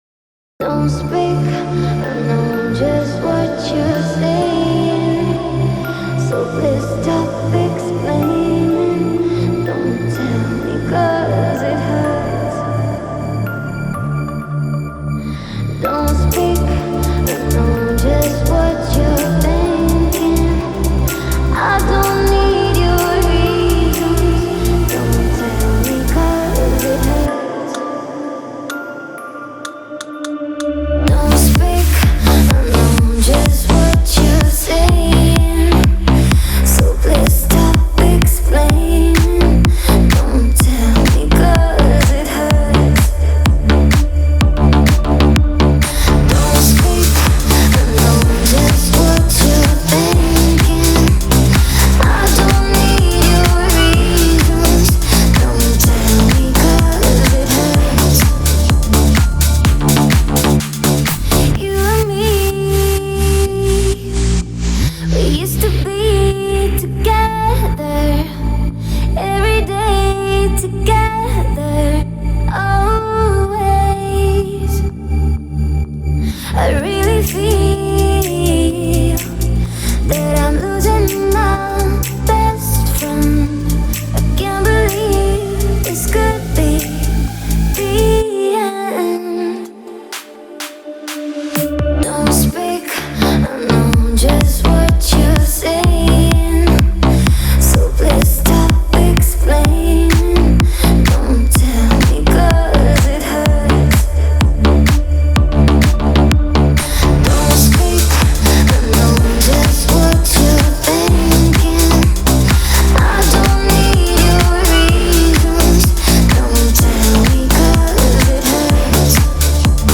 которая сочетает элементы поп-рока и электронной музыки.